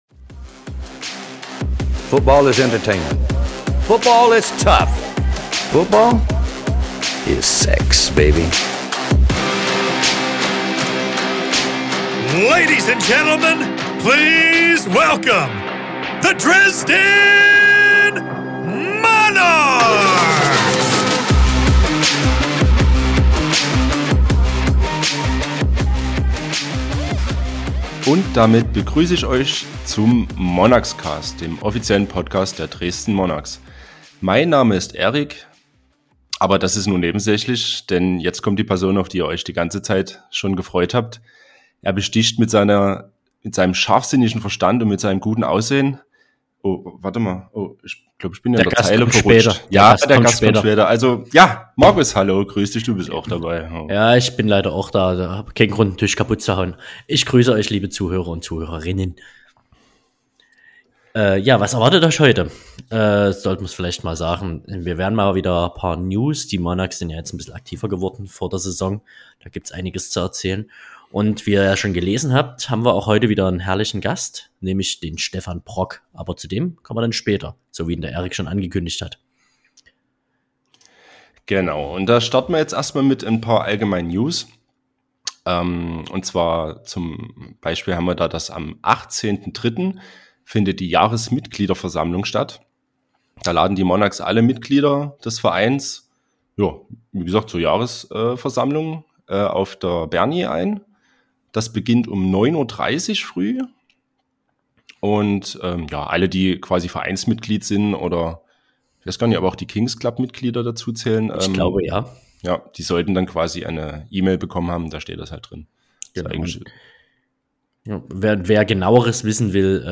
Wir informieren euch in der GFL freien Zeit einmal monatlich mit einer Folge, in welcher wir euch über aktuelle News auf dem Laufenden halten. Außerdem wird immer ein interessanter Gast in einem Interview Rede und Antwort stehen.